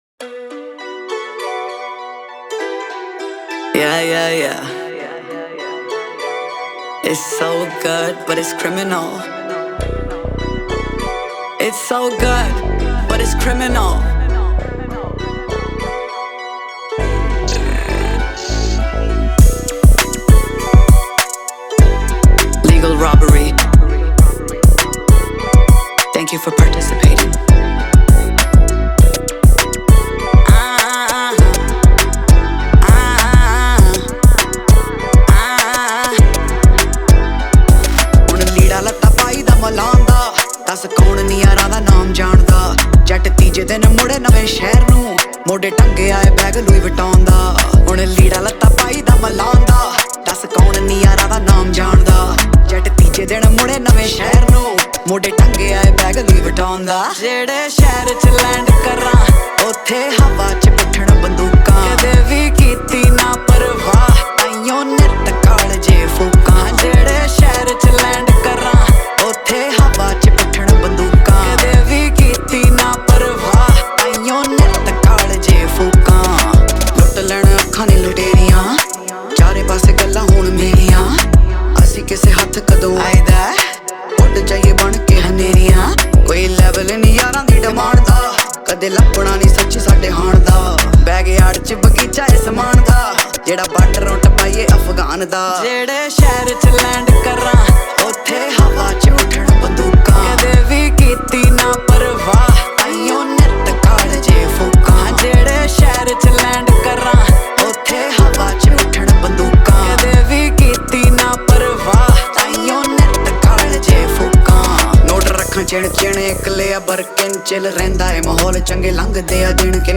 Category: Punjabi